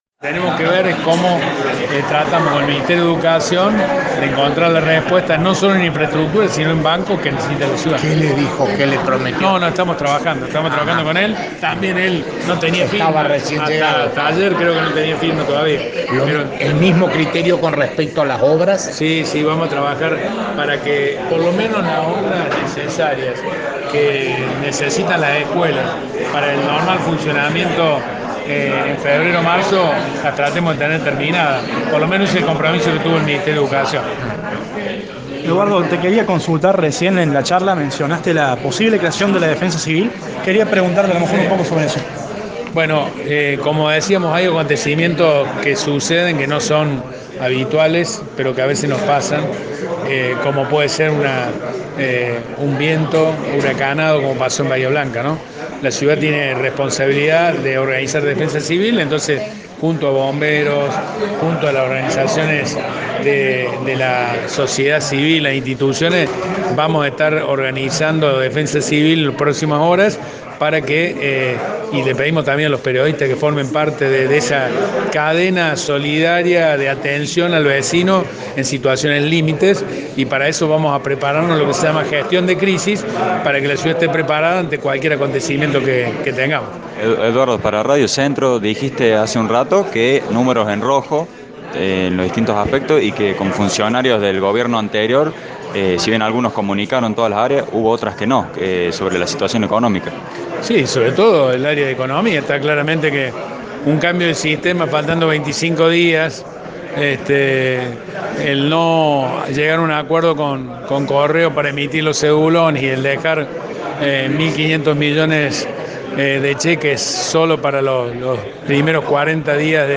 En un desayuno que tuvo lugar en el Salón Juan Domingo Perón del Palacio Municipal, el jefe comunal, junto a parte de su gabinete, destacó la importancia de la comunicación y el acceso a la información como derecho, al mismo tiempo que valoró la profesión periodística como el medio para hacer llegar las políticas públicas de la gestión municipal a vecinos y vecinas.
Eduardo-Accastello.mp3